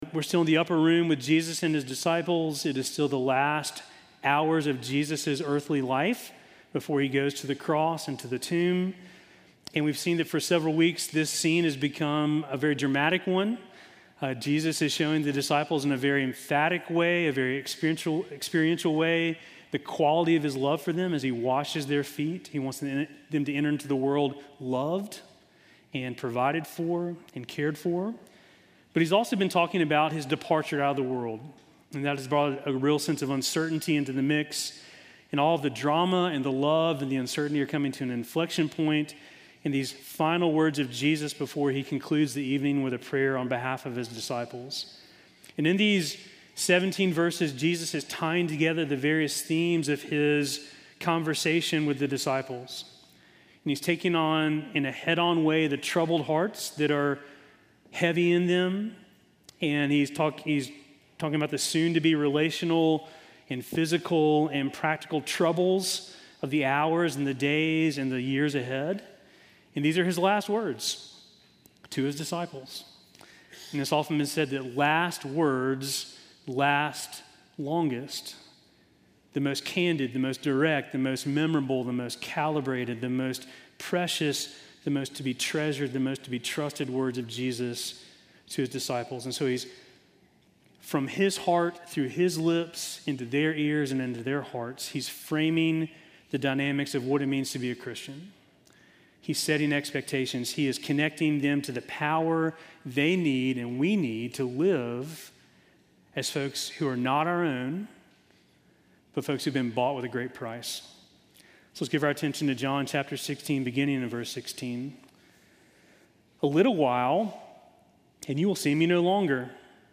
Sermon from March 8